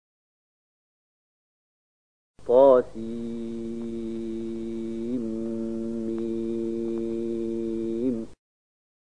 026001 Surat Asy-Syu`araa’ ayat 1 dengan bacaan murattal ayat oleh Syaikh Mahmud Khalilil Hushariy: